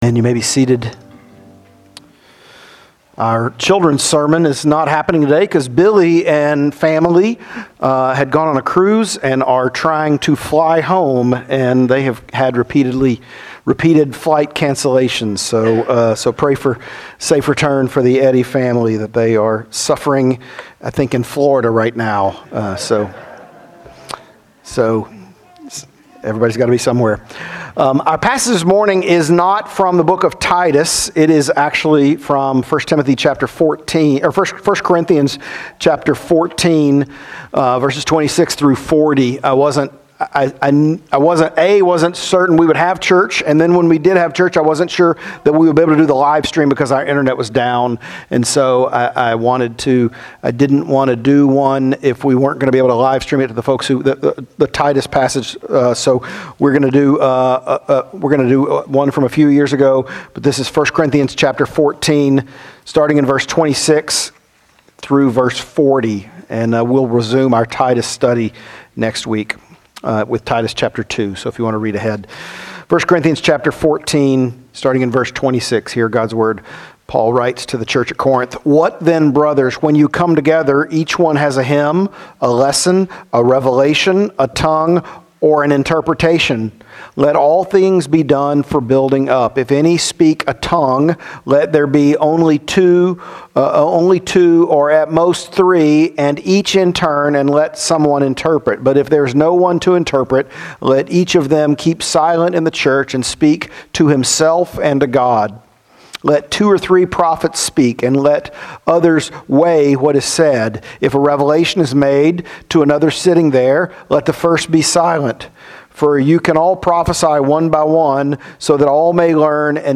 1 Corinthians 14:26-40 Sermon